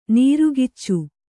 ♪ nīrugiccu